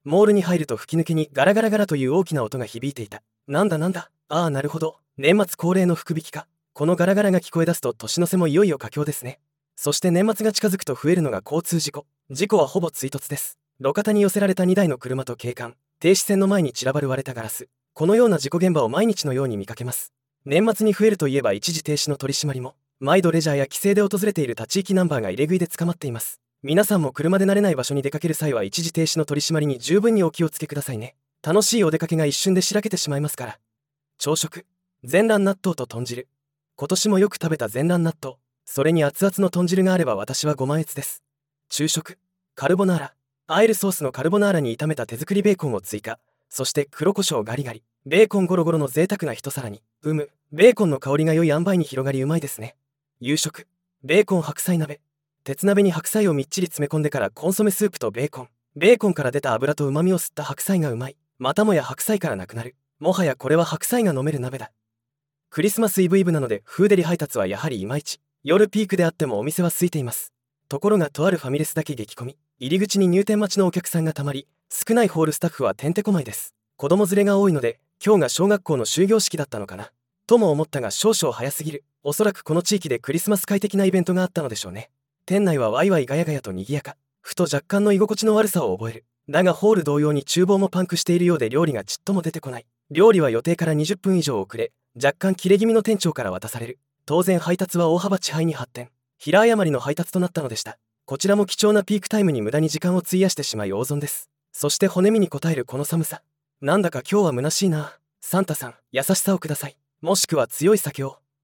モールに入ると吹き抜けに「ガラガラガラ」という大きな音が響いていた。